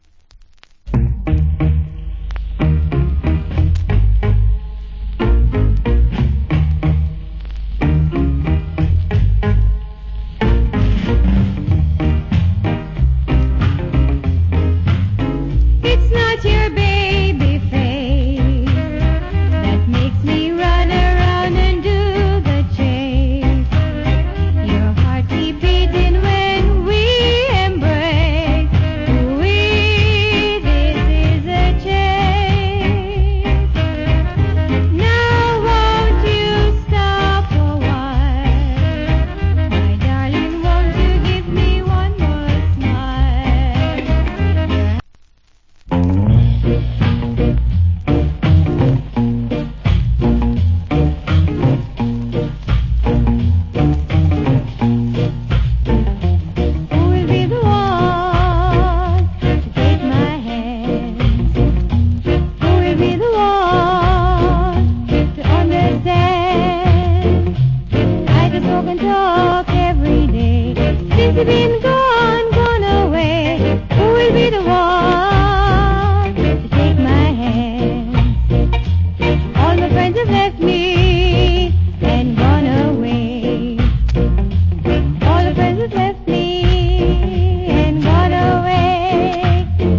Wicked Female Rock Steady Vocal.